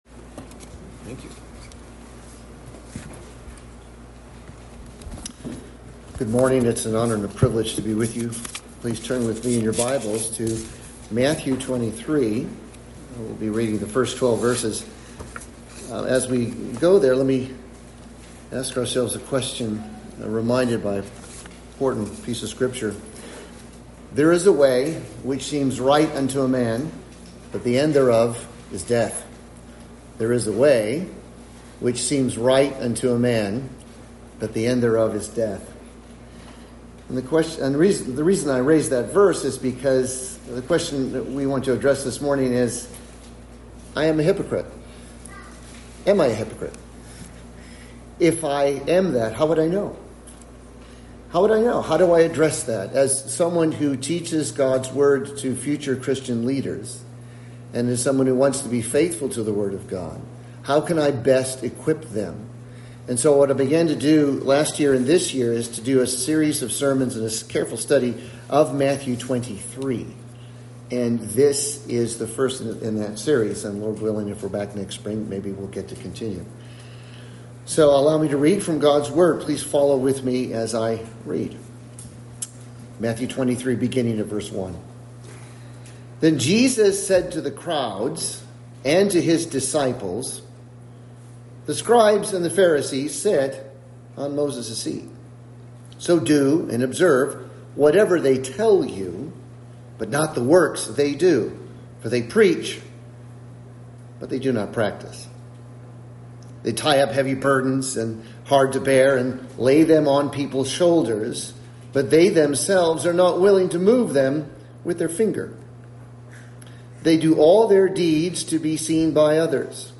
Series: Guest Preacher
Service Type: Morning Service